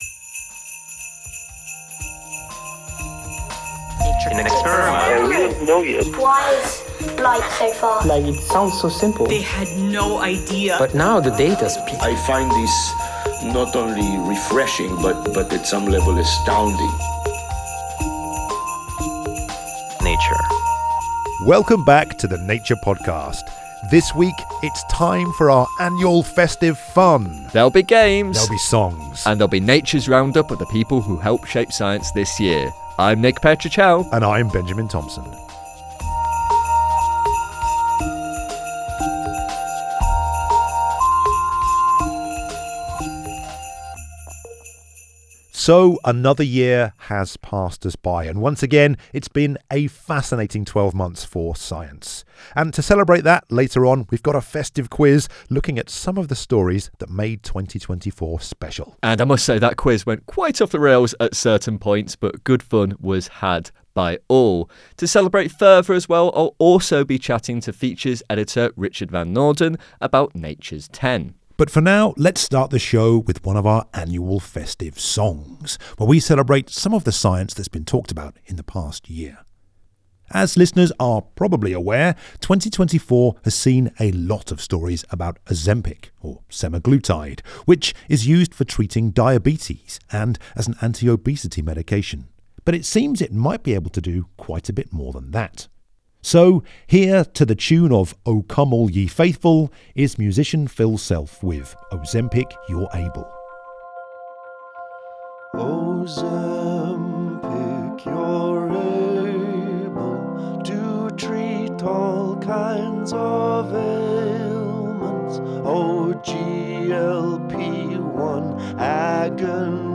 In the first of our annual festive songs celebrating the science of the past year, we pay homage to Ozempic, or semaglutide, that’s able to tackle obesity, diabetes and potentially a whole lot more.